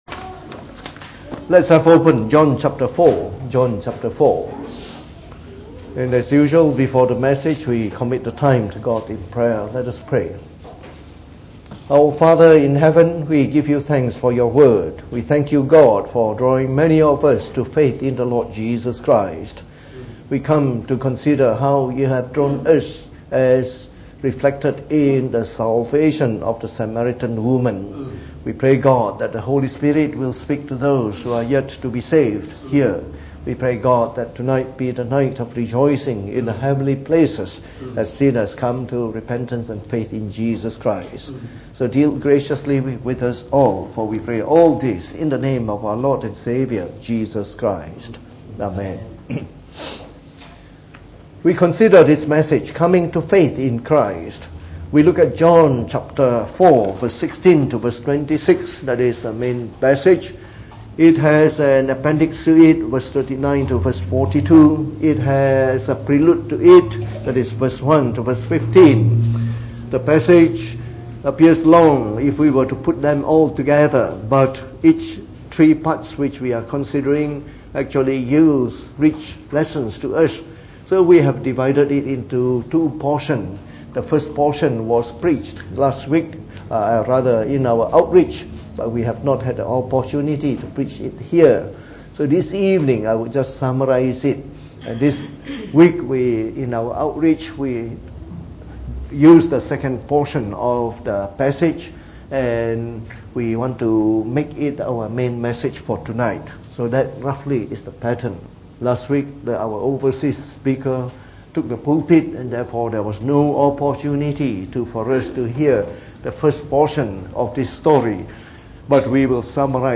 From our series on the Gospel of John delivered in the Evening Service.